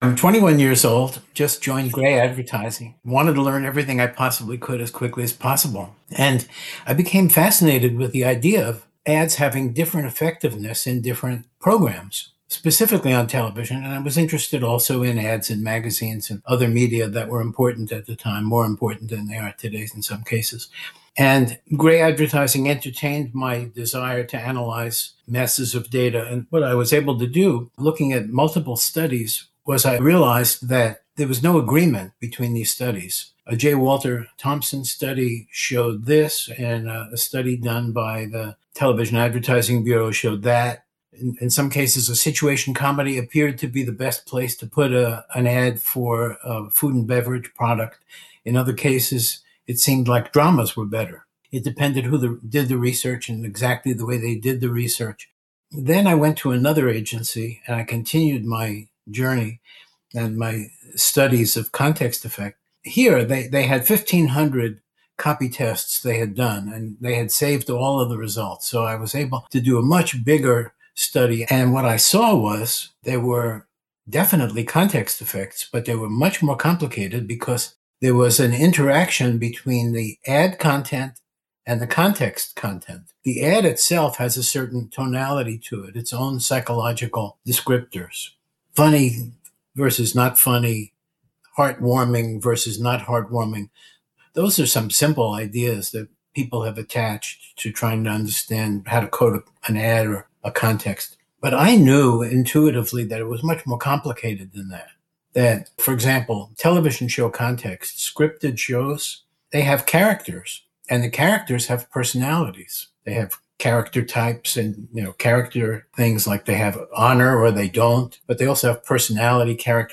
Full Interview - Emotions, motivators and drivers: what matters most in marketing